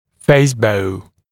[‘feɪsbəu][‘фэйсбоу]лицевая дуга